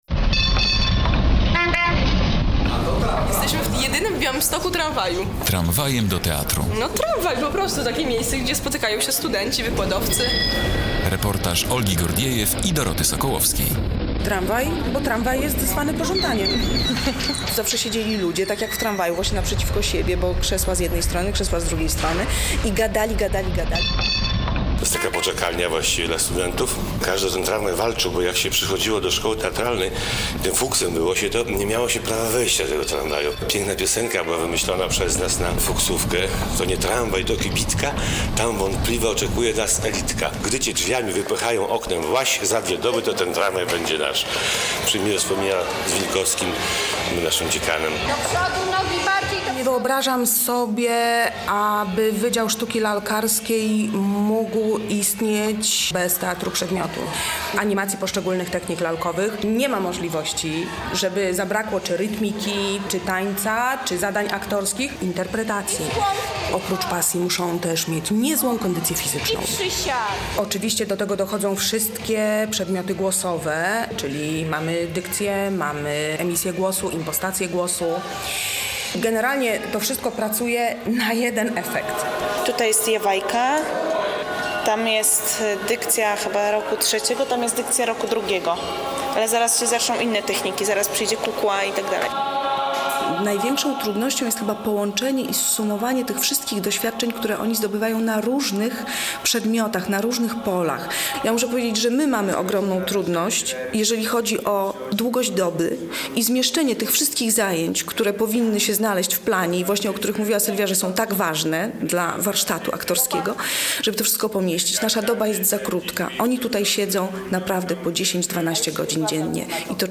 Radio Białystok | Reportaż